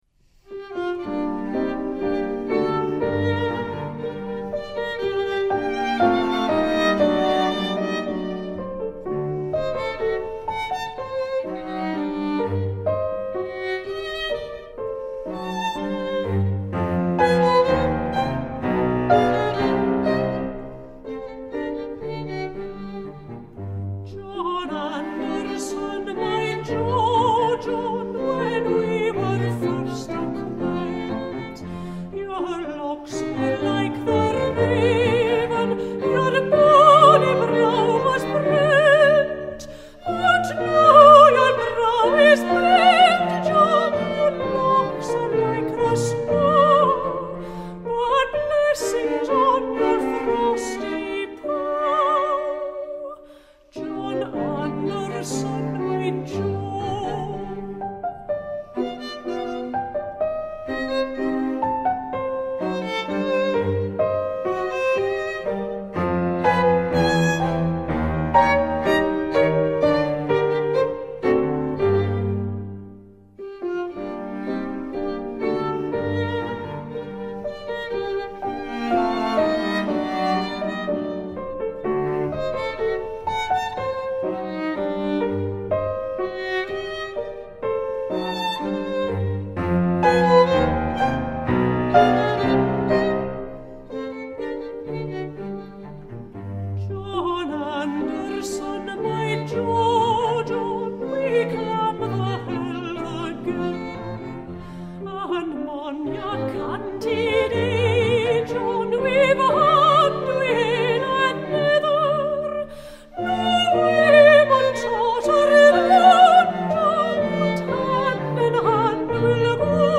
Folk song arrangement
Group: Classical vocal Composers of classical music developed a strong interest in traditional song collecting, and a number of outstanding composers carried out their own field work on traditional song. Haydn - Scottish songs